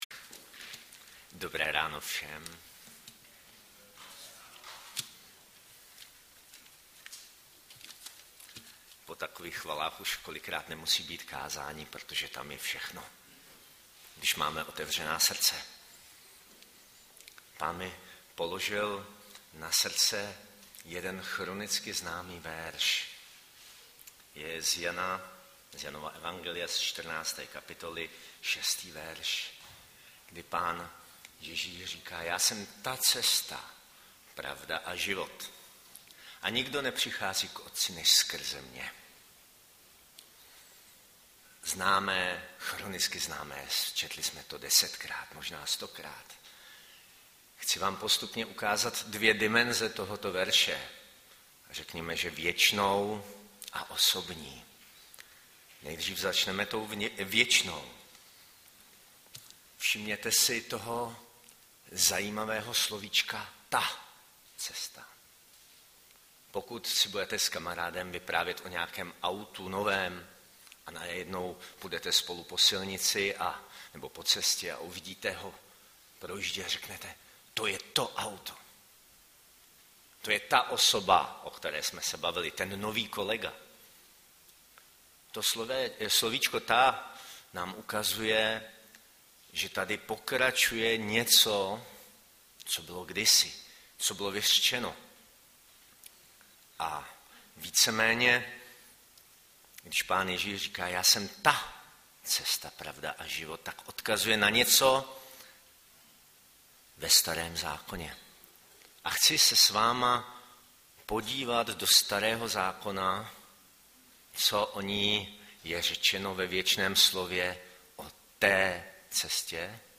Audiozáznam kázání